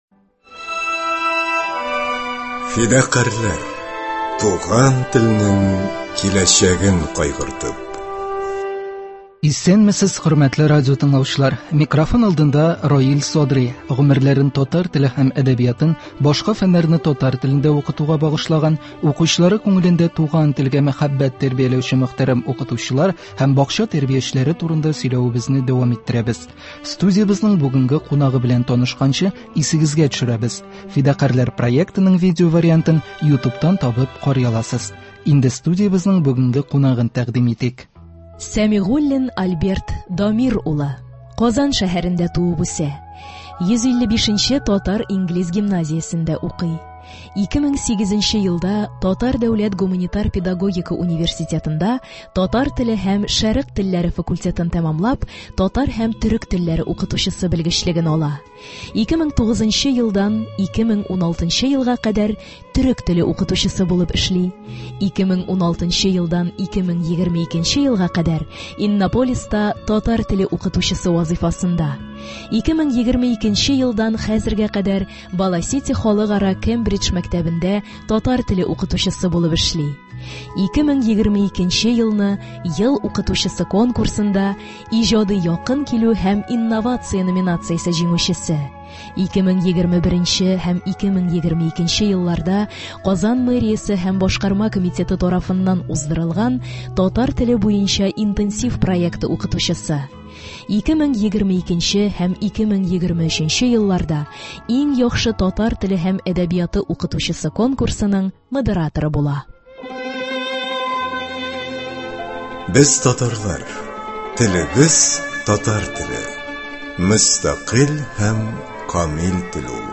Студиябезнең бүгенге кунагы